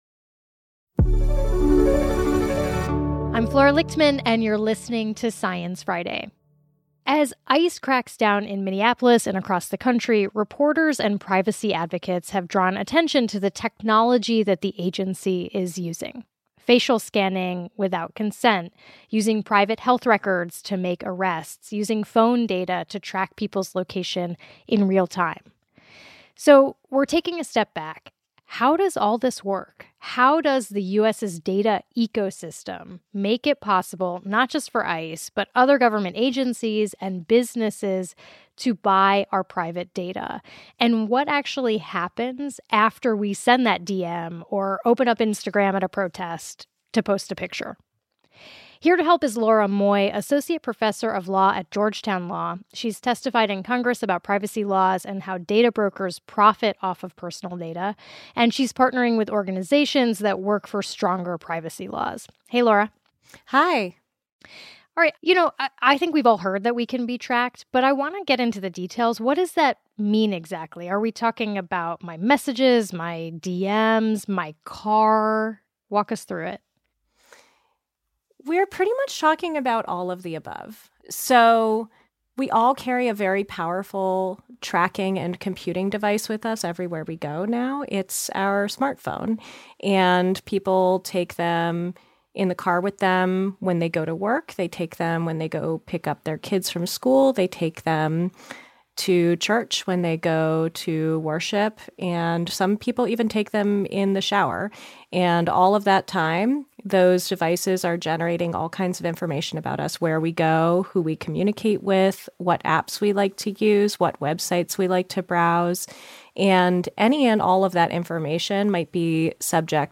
sits down with law professor and tech policy expert